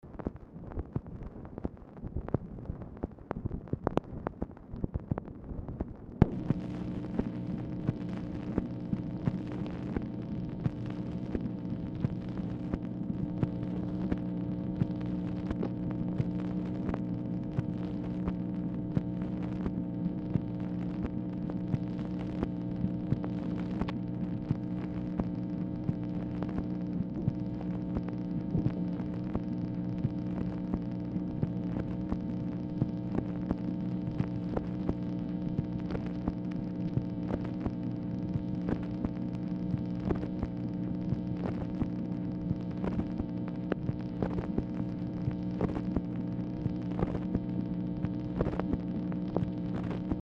Telephone conversation # 5935, sound recording, MACHINE NOISE, 10/21/1964, time unknown
MACHINE NOISE
Oval Office or unknown location
Telephone conversation
Dictation belt